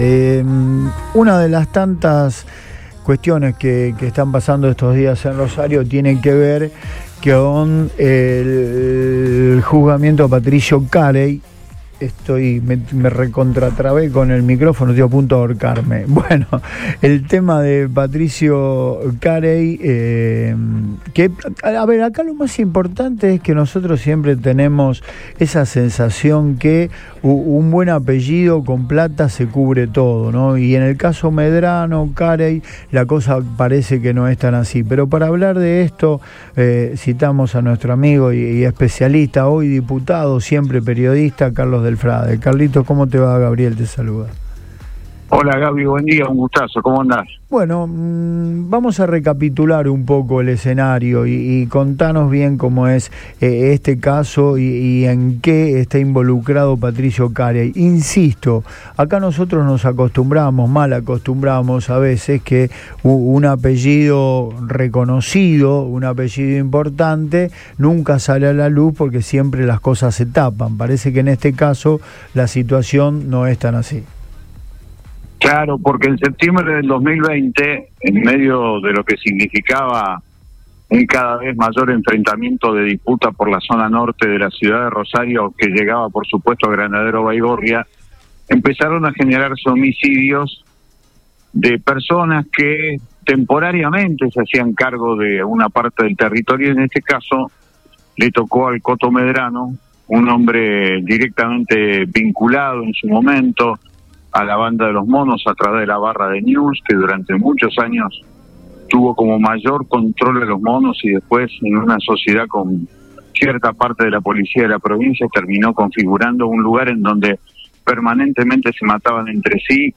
comenzó Del Frade en Antes de Todo de Radio Boing.